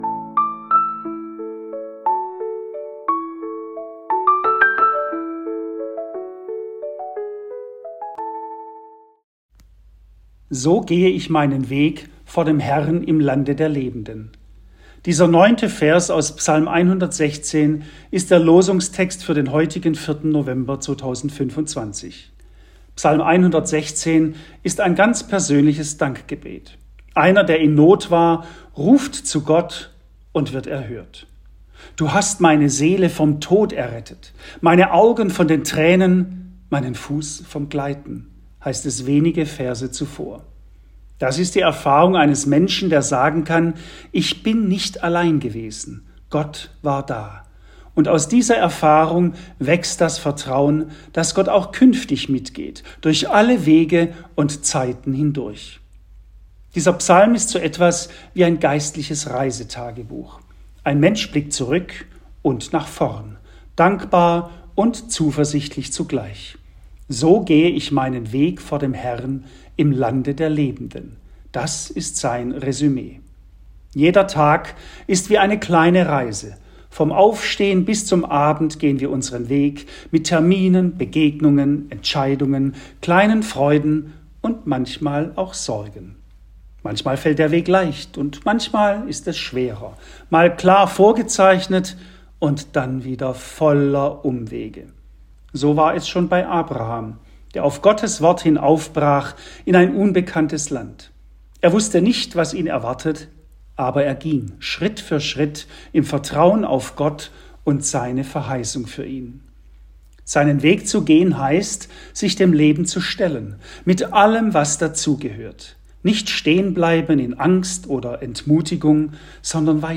Losungsandacht für Dienstag, 04.11.2025